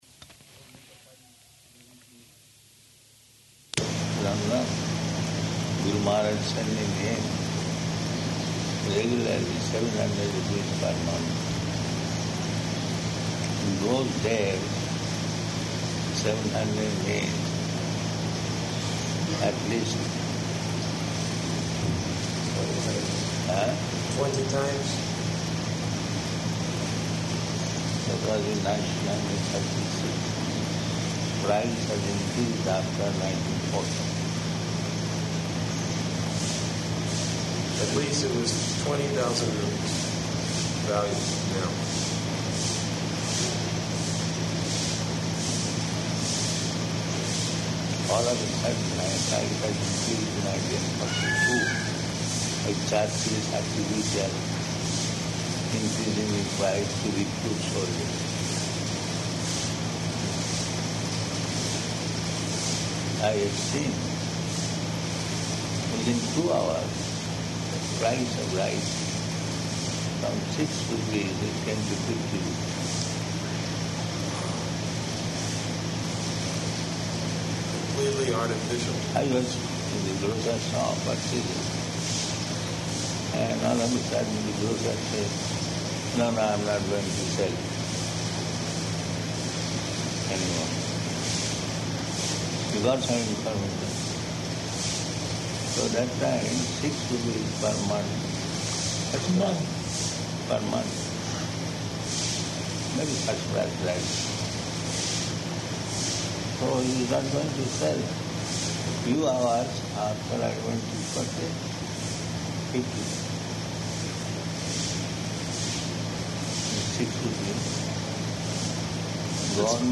Short Discussions --:-- --:-- Type: Conversation Dated: May 24th 1977 Location: Vṛndāvana Audio file: 770524R2.VRN.mp3 Prabhupāda: ...London. Guru Mahārāja sending him regularly seven hundred rupees per month.